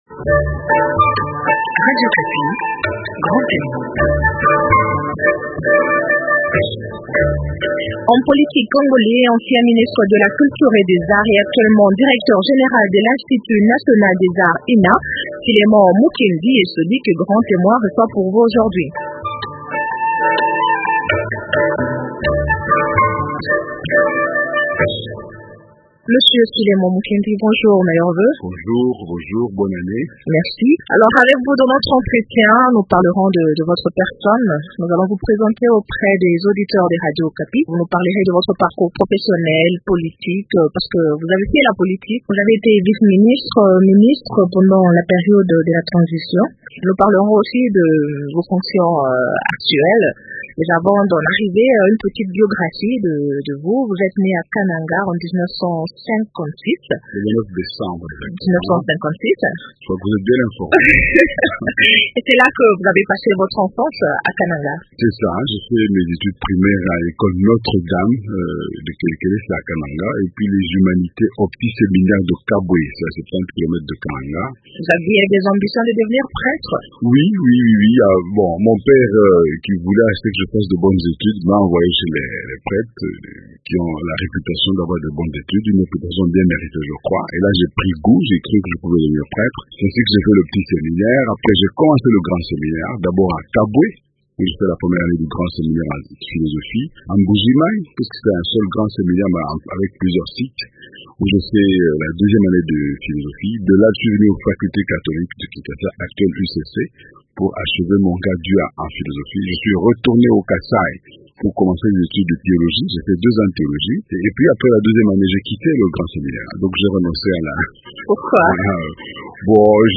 Homme politique congolais, ancien ministre de la Culture et des arts, Philémon Mukendi est actuellement directeur général de l’institut national des arts